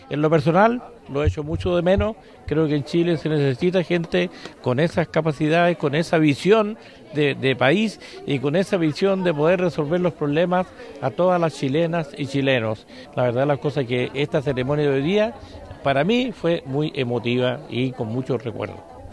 El diputado por Los Ríos ligado a Chile Vamos, Bernardo Berger, afirmó que Chile necesita una persona que resuelva las necesidades de la ciudadanía, tal como lo hizo, según indicó, el expresidente Sebastián Piñera.